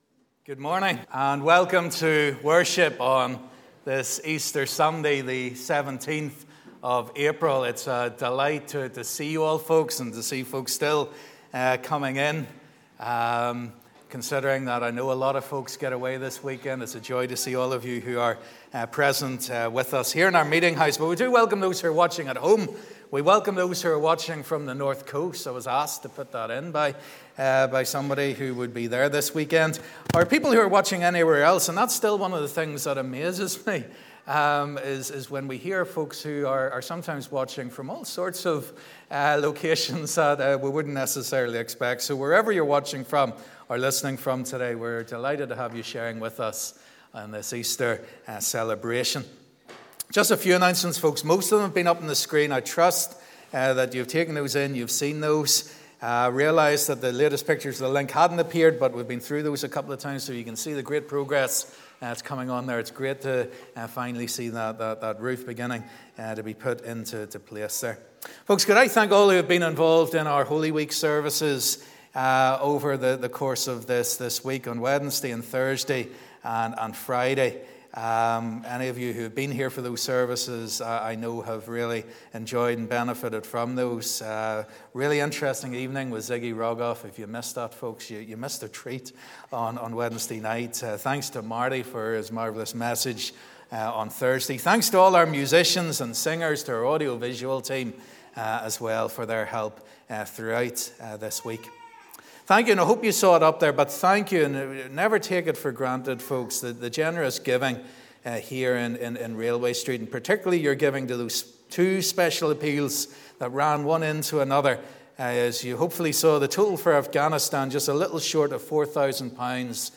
In this family service we want to think about what this new life looks like.